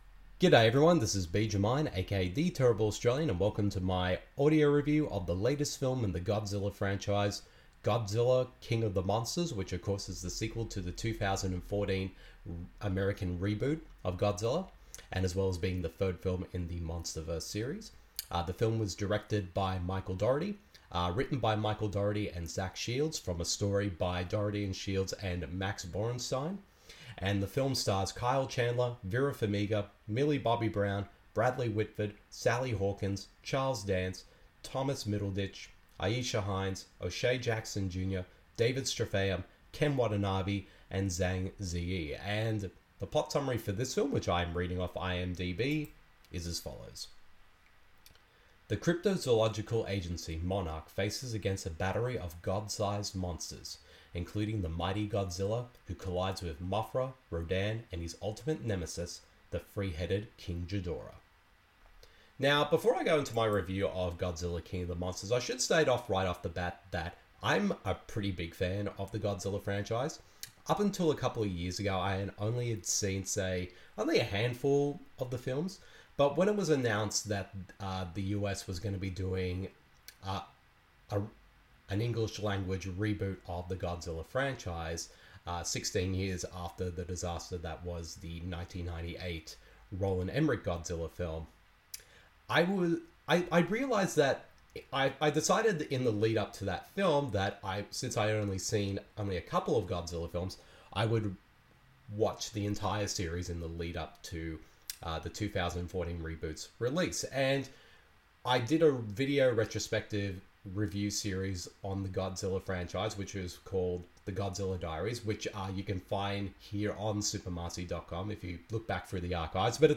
[Audio Review] Godzilla: King Of The Monsters (2019)